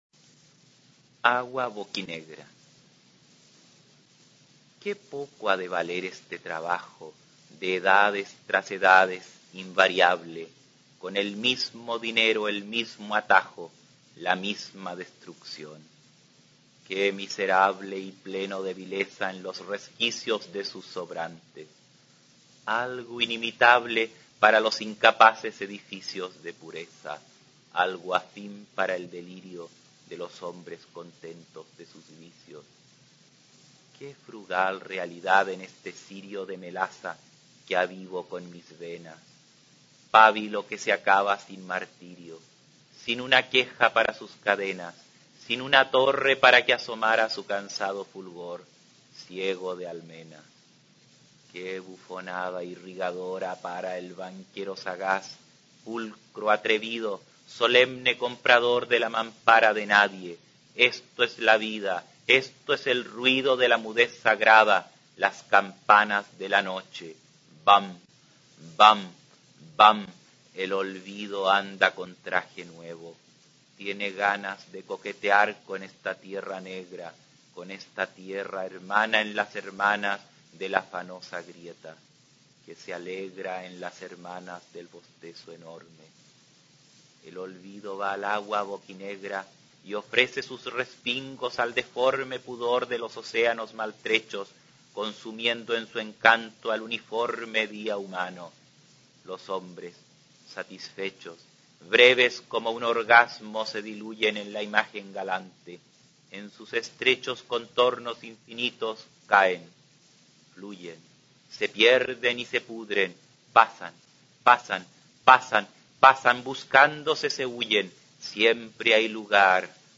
Aquí se puede oír al escritor chileno David Rosenmann-Taub leyendo su poema Agua Boquinegra. El autor es una de las voces más importantes de la literatura chilena e hispanoamericana, aunque no ha tenido la difusión que merece en su país natal.